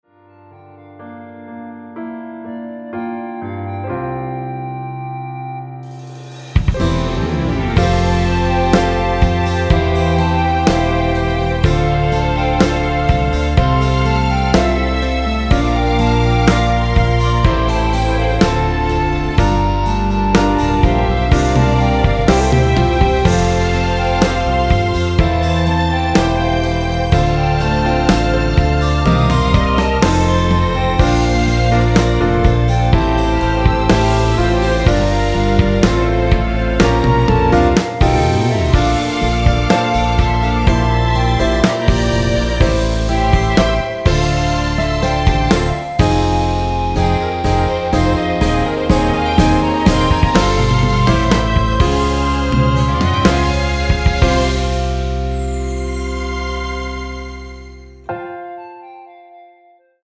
원키에서(+4)올린 멜로디 포함된 1절후 후렴으로 진행 되는 MR입니다.(본문 가사 확인)
앞부분30초, 뒷부분30초씩 편집해서 올려 드리고 있습니다.
중간에 음이 끈어지고 다시 나오는 이유는